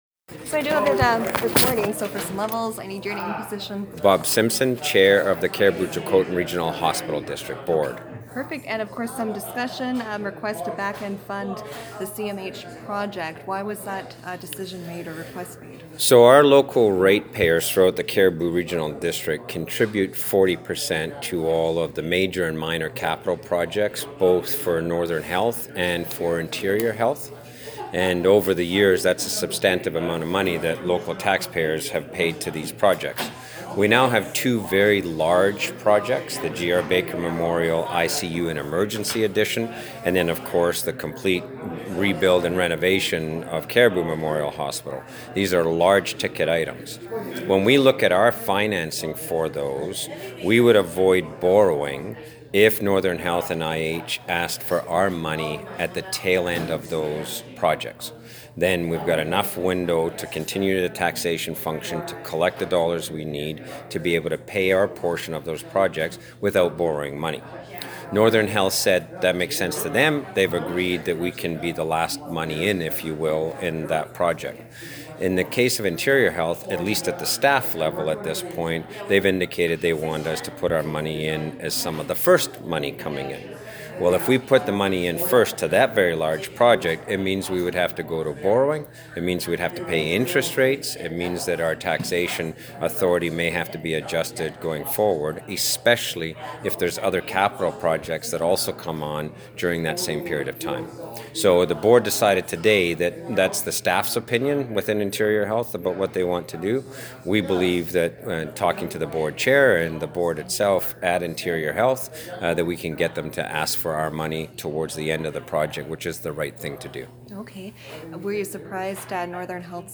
(Note: Listen to the author of this report with CCRHD board chair Bob Simpson in the audio file below)